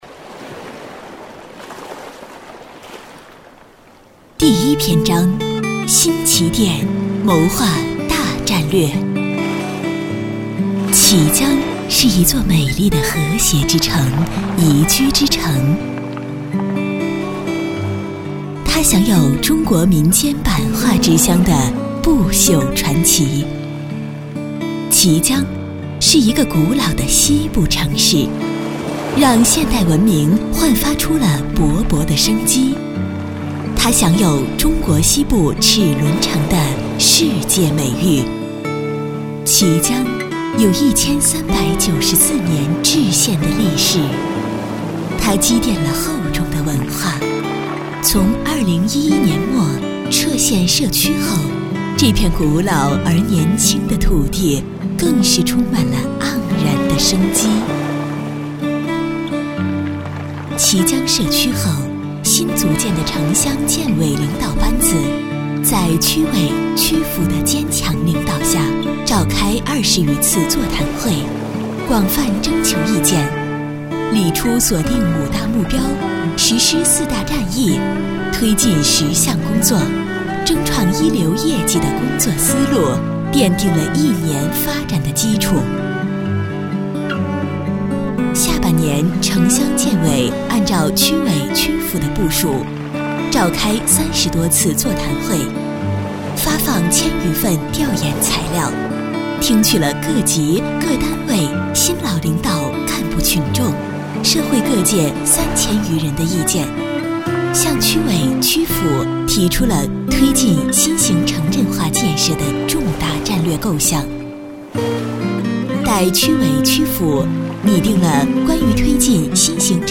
技工学校 特 点：年轻活力,时尚高端,成熟知性
号数：15号女